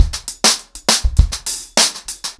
BellAir-A-100BPM__1.3.wav